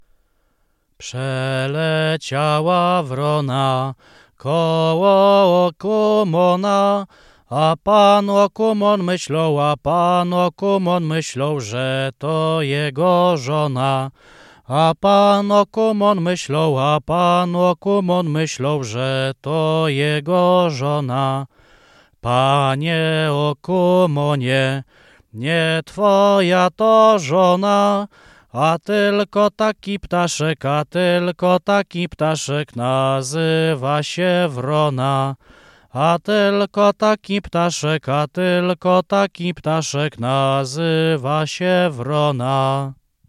Dożynkowa